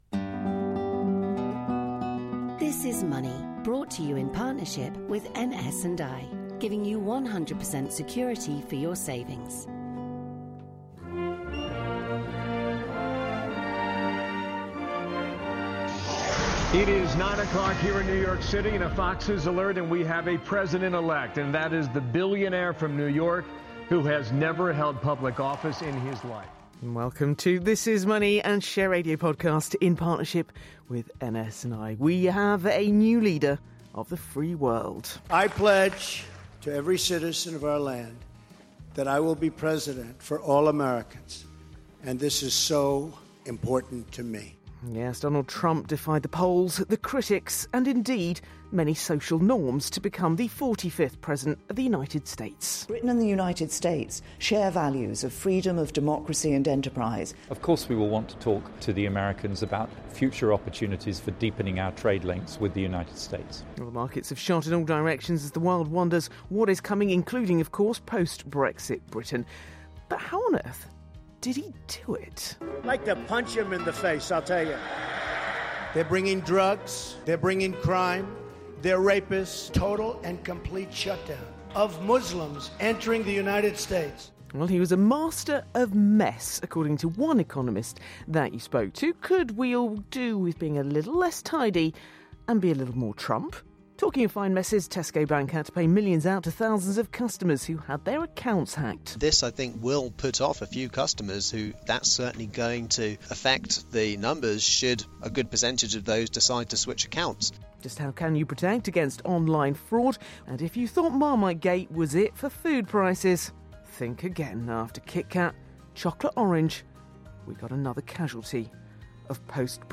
Share Radio studio